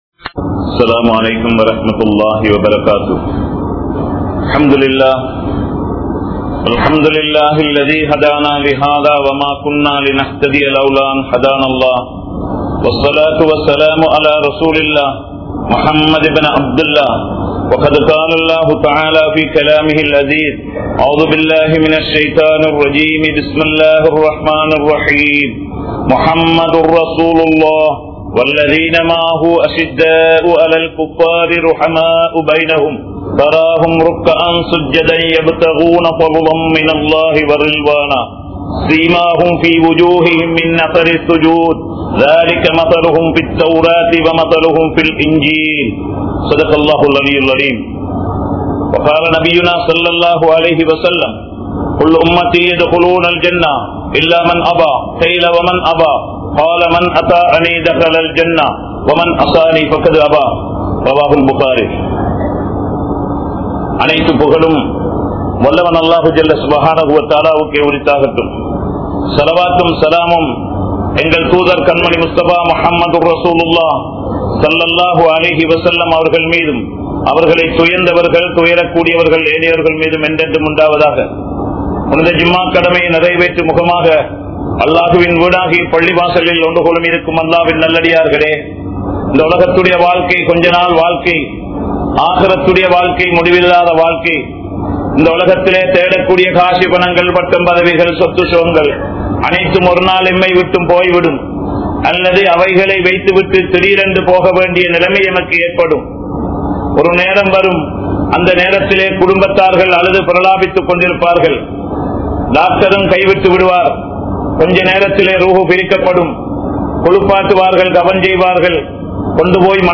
Atputhamaana Manithar Nabi(SAW)Avarhal (அற்புதமான மனிதர் நபி(ஸல்) அவர்கள்) | Audio Bayans | All Ceylon Muslim Youth Community | Addalaichenai
Kollupitty Jumua Masjith